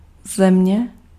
Ääntäminen
Synonyymit grond bodem bol wereldbol wereld planeet aardrijk aardbol humus globe terra aardmolm land klei fond voedingsbodem aardbodem Ääntäminen : IPA: [aːɾ.də] Tuntematon aksentti: IPA: /a:ɾ.də/ IPA: /ˈardə/